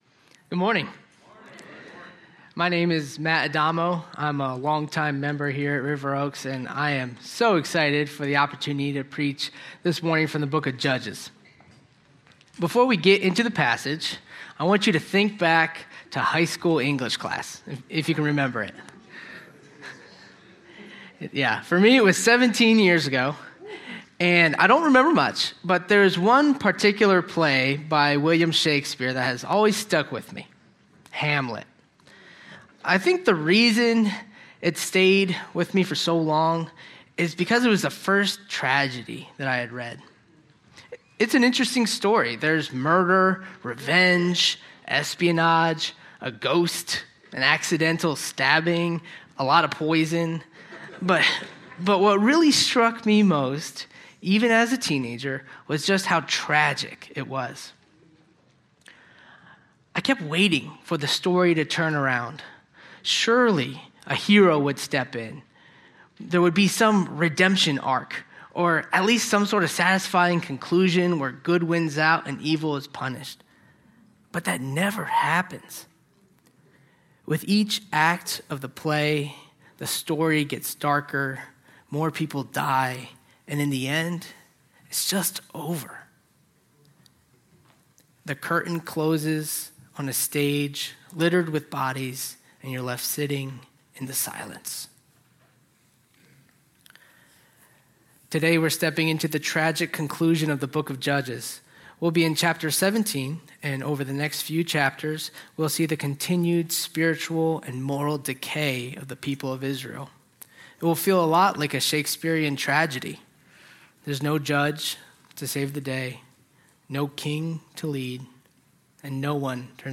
A sermon on Judges 17:1-13